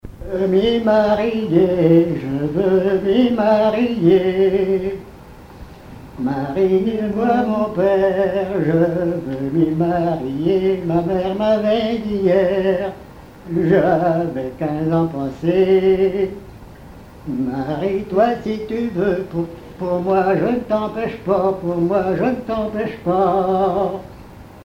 Chansons traditionnelles
Pièce musicale inédite